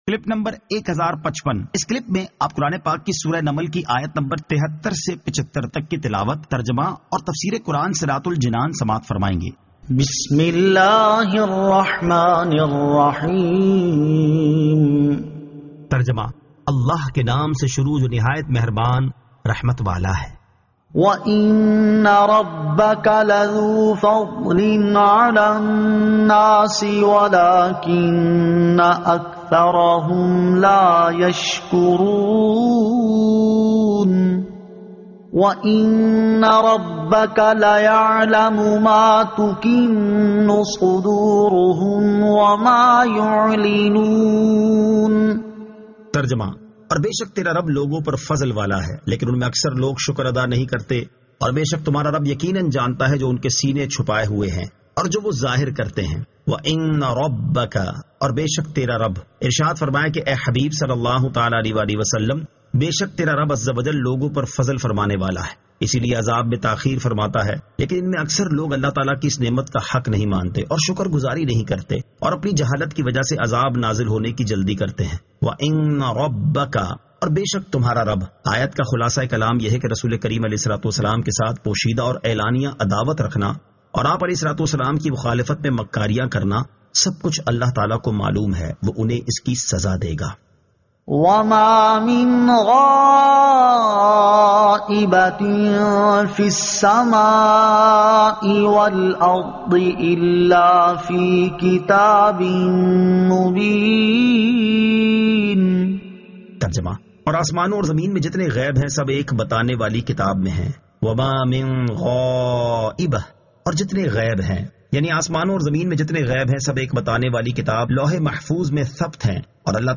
Surah An-Naml 73 To 75 Tilawat , Tarjama , Tafseer